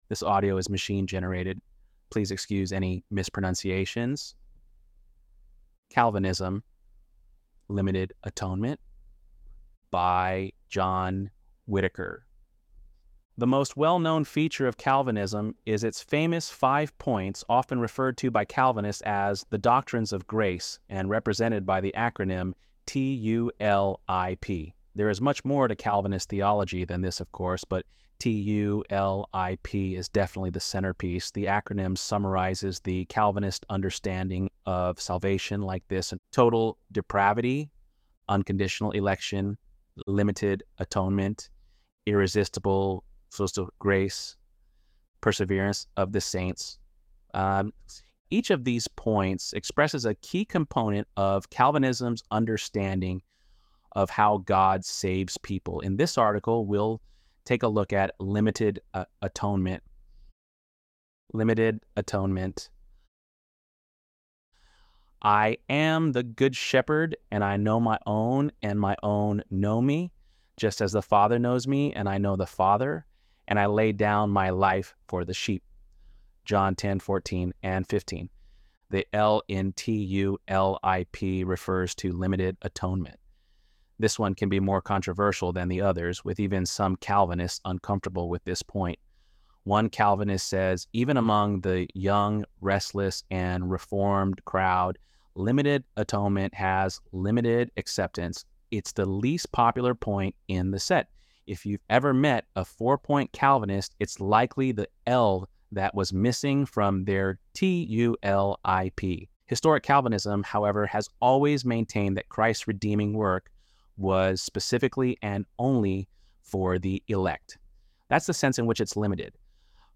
ElevenLabs_6.19_TULIP_Pt.mp3